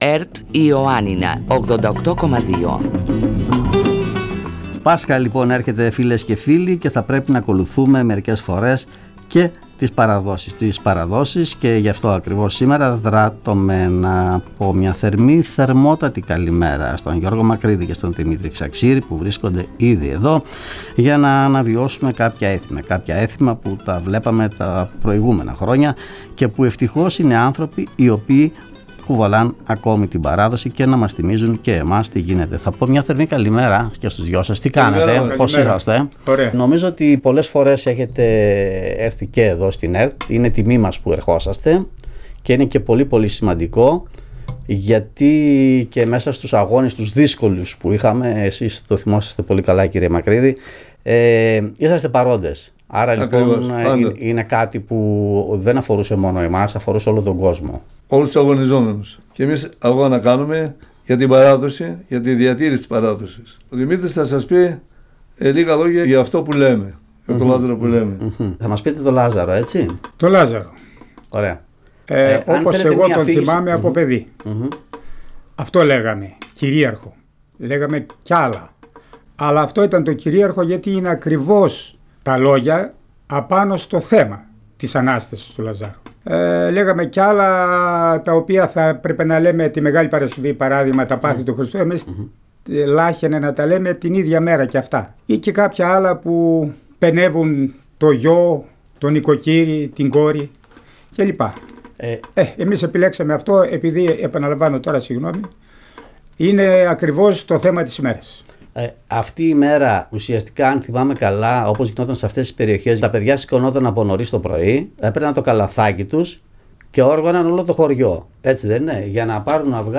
τραγουδήσουν ζωντανά
παραδοσιακά Κάλαντα
Ντυμένοι με παραδοσιακές φορεσιές, τιμούν με την παρουσία τους κάθε χρόνο τον Περιφερειακό Σταθμό Ιωαννίνων, βάζοντας τους ακροατές και τις ακροάτριες στο κλίμα των εορτών.